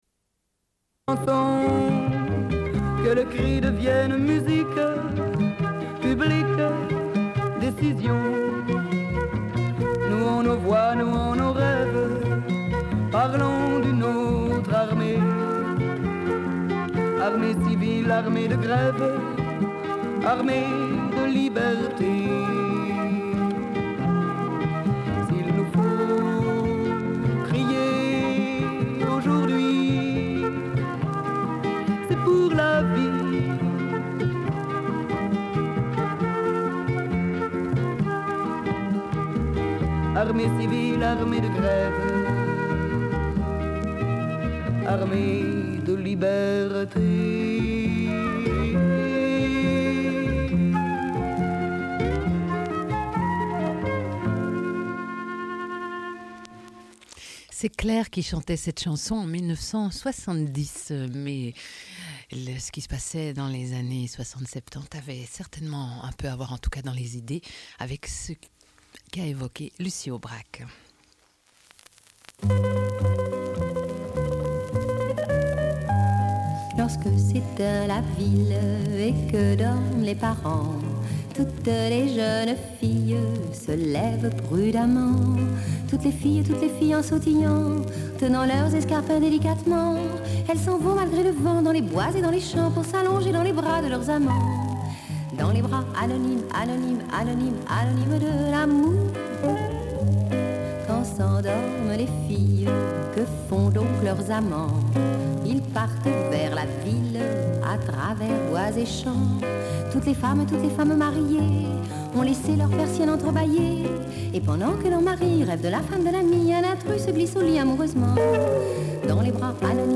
Suite de l'émission : diffusion d'une rencontre avec Lucie Aubrac à l'occasion d'une conférence au Collège de Staël sur les femmes et la résistance en France.